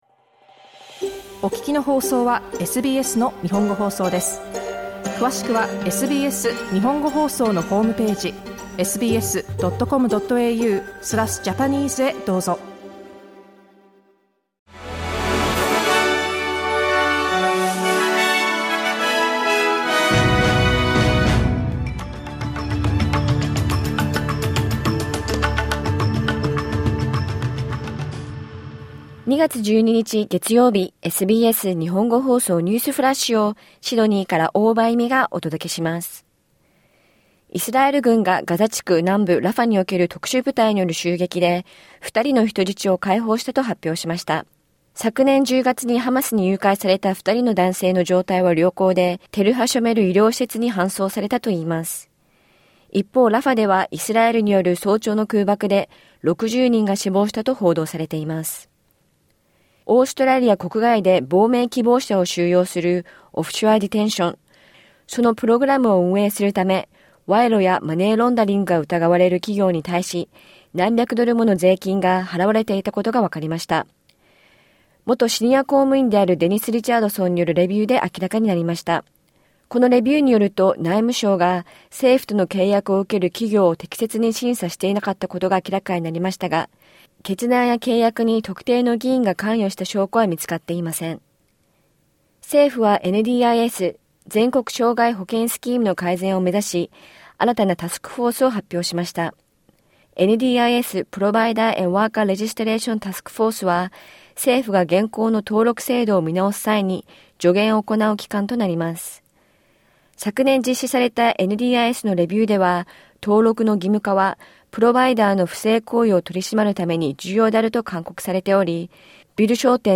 SBS日本語放送ニュースフラッシュ 2月12日月曜日